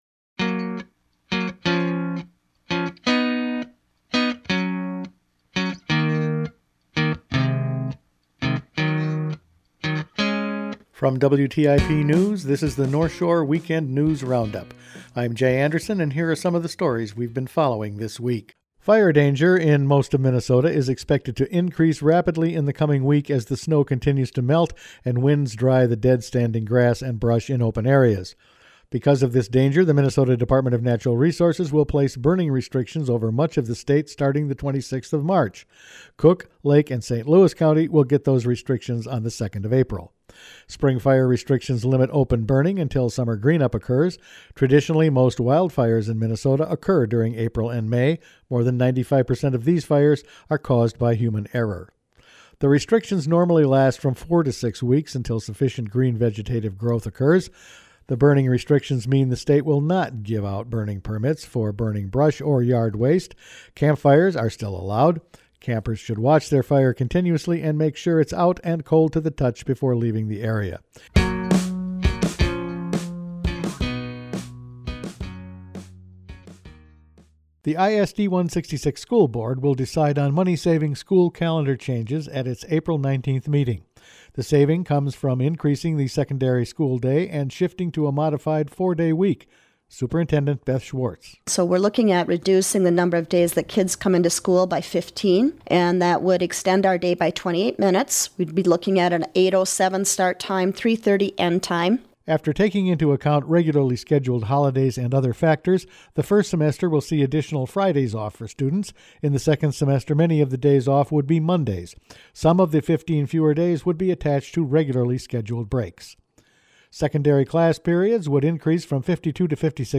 Each weekend WTIP news produces a round up of the news stories they’ve been following this week Fire restrictions to go on, ISD166 to start a hybrid 4-day calendar, the Park Service looks at Isle Royale wolves and Rep. David Dill talks about a Constitutional Amendment…all in this week’s news.